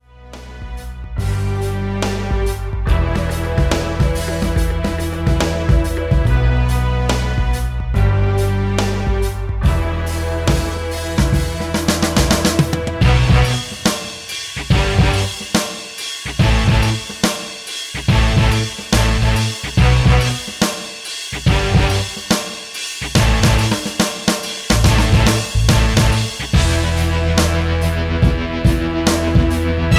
Backing track files: Musical/Film/TV (484)